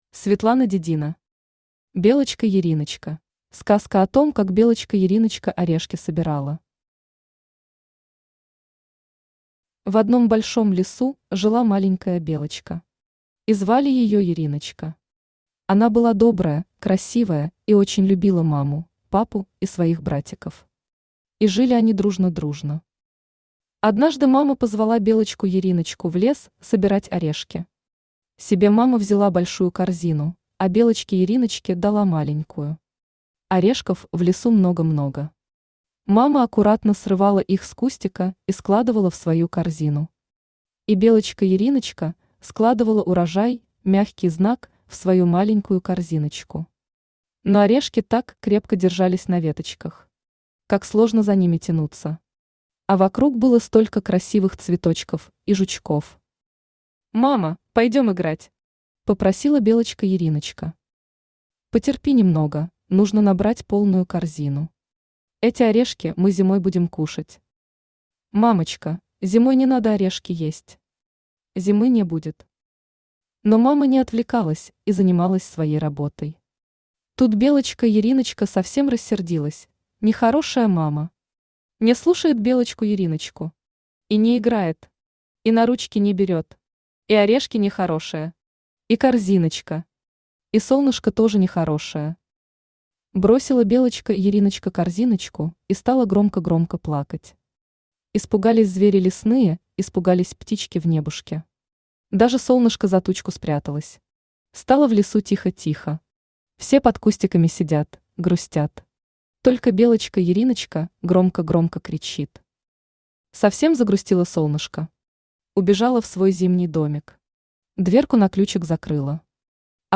Аудиокнига Белочка Яриночка | Библиотека аудиокниг